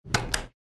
Звуки лифта
Звук нажатия кнопки лифта